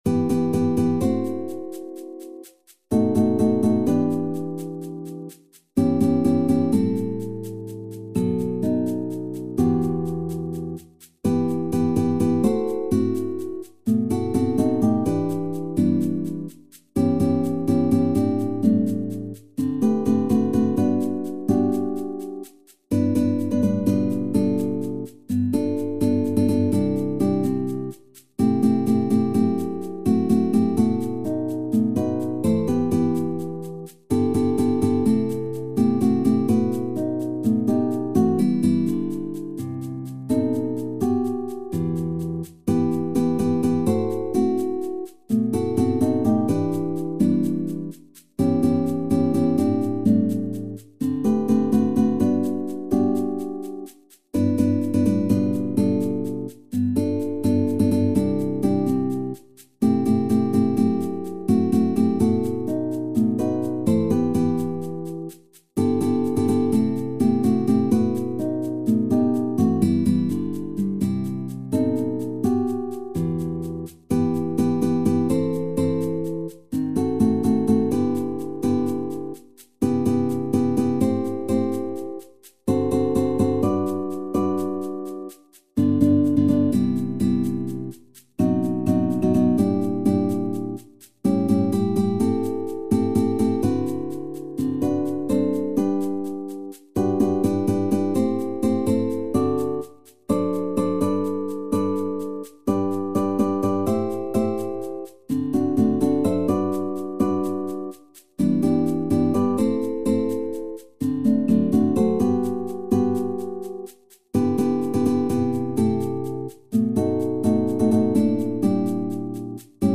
SSATB | SSAA | SATTB | SATB | SSAB
Ein ruhiges Lied